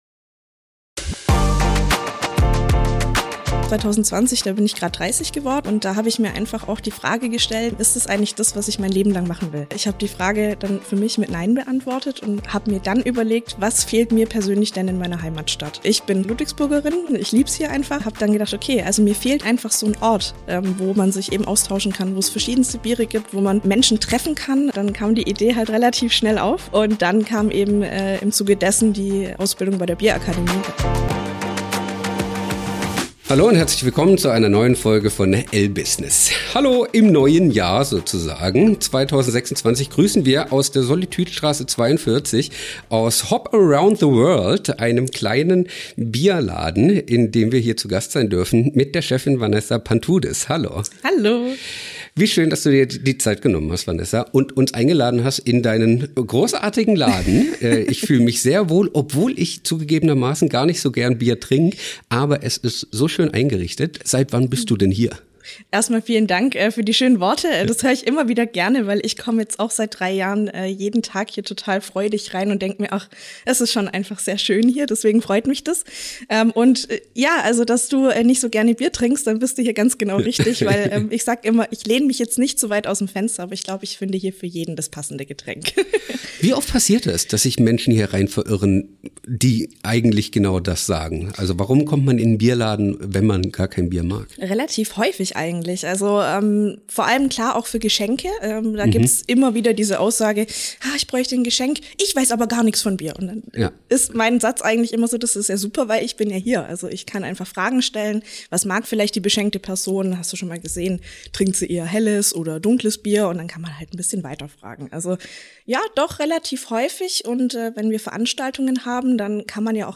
Ein Gespräch, das neugierig macht und Lust auf neue Aromen weckt.